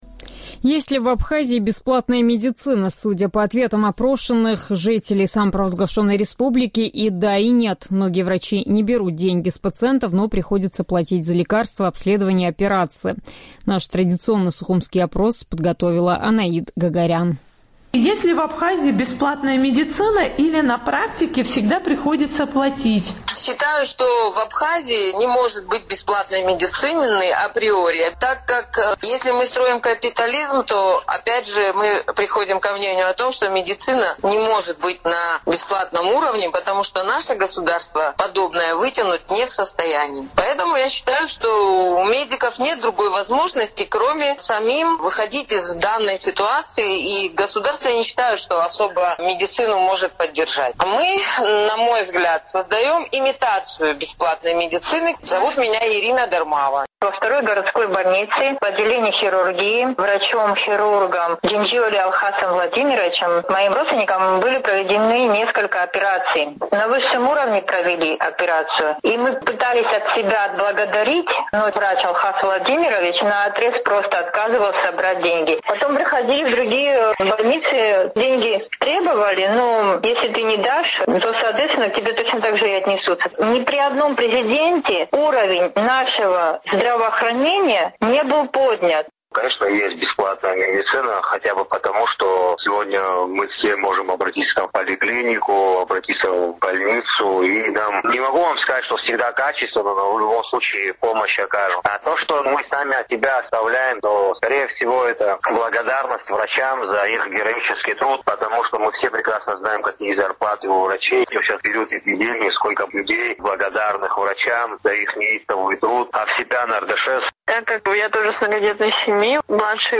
Судя по ответам опрошенных «Эхом Кавказа» местных жителей, и да, и нет: многие врачи не берут деньги с пациентов, но приходится платить за лекарства, обследования, операции и т.д.